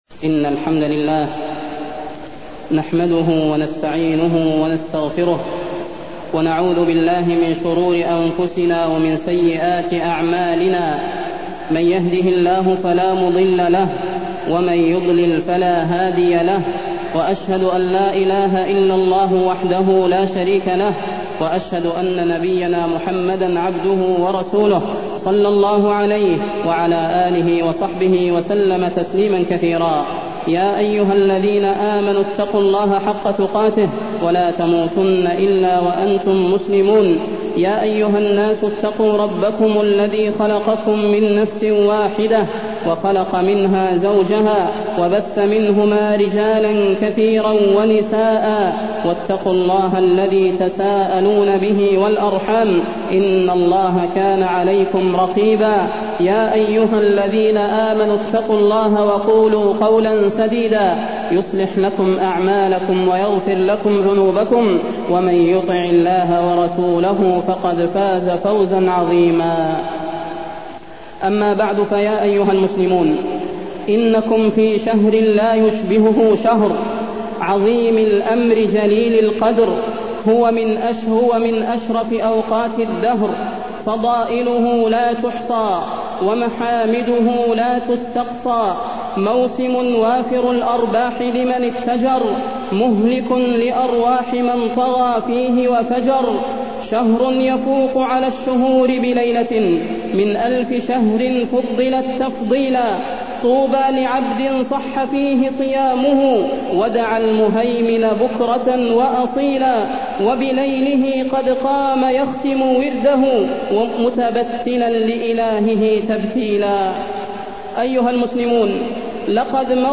فضيلة الشيخ د. صلاح بن محمد البدير
تاريخ النشر ١٥ رمضان ١٤٢٢ هـ المكان: المسجد النبوي الشيخ: فضيلة الشيخ د. صلاح بن محمد البدير فضيلة الشيخ د. صلاح بن محمد البدير إنتصاف شهر رمضان